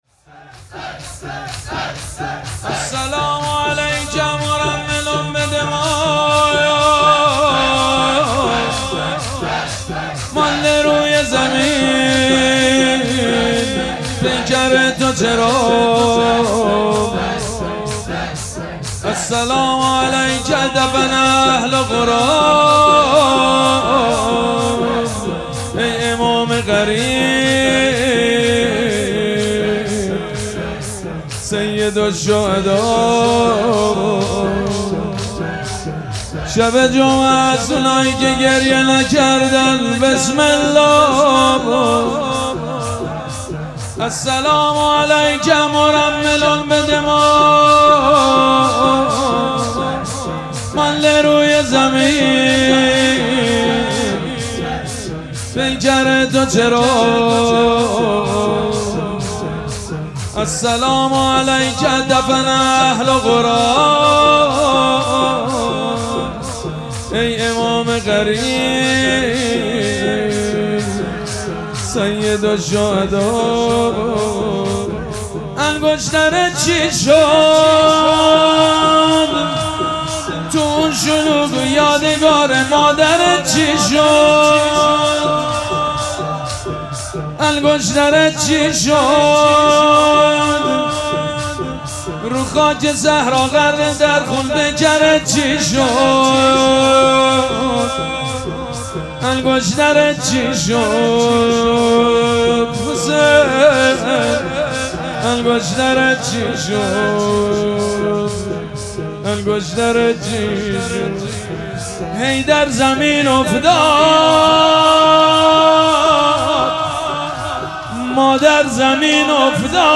السلام علیک مرمل بالدماء - شور
فاطمیه 1403